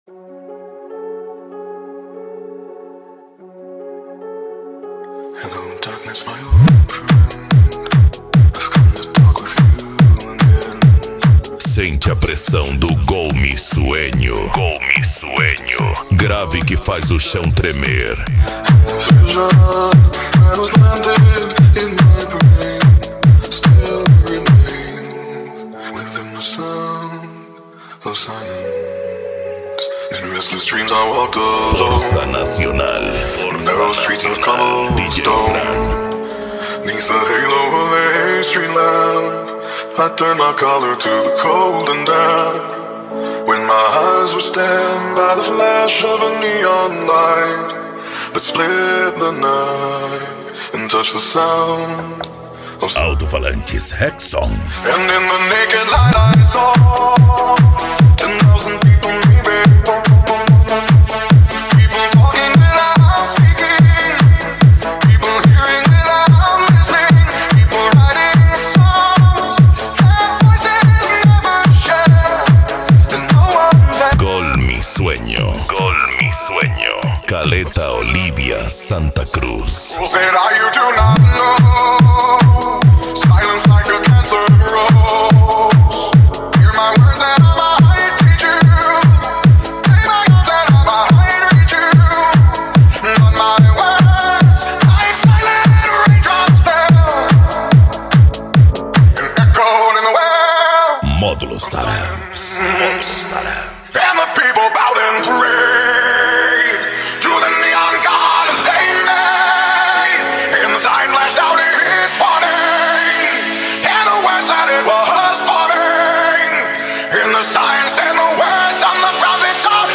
Bass
Psy Trance
Remix